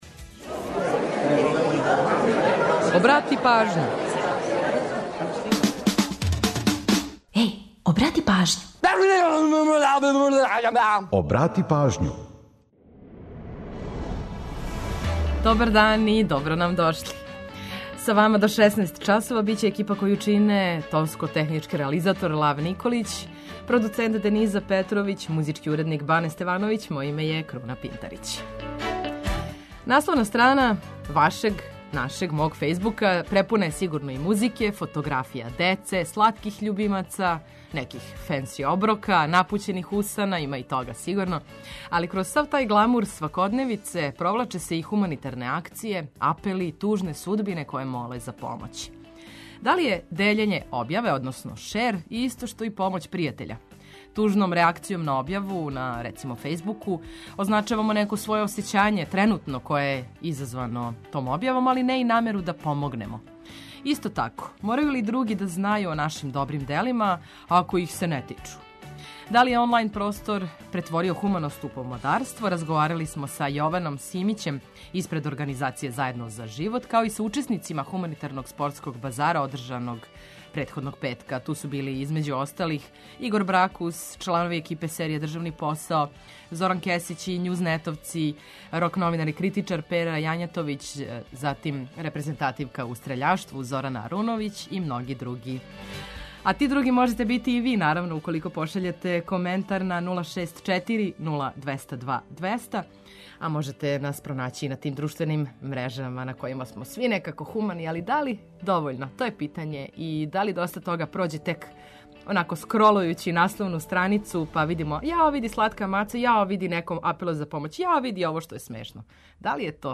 Корисне информације и омиљена музика су обавезни детаљ.